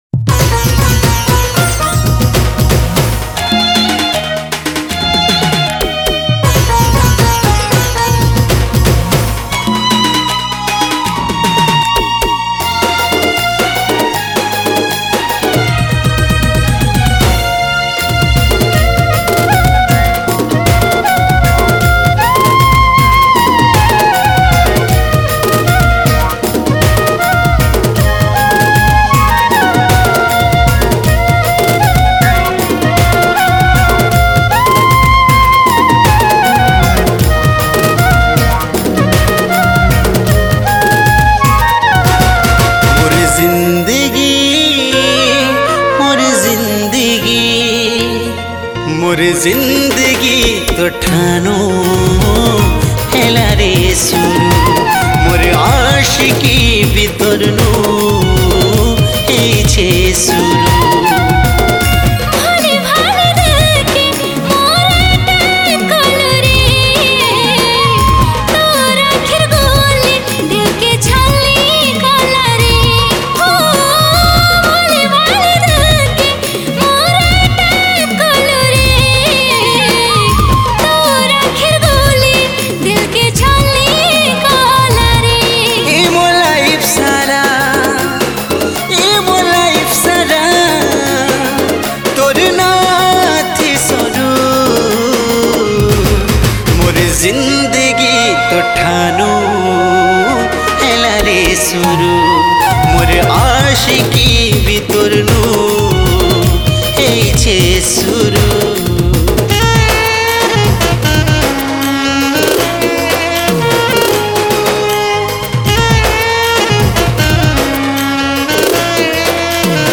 Category : Sambapuri Single Song 2022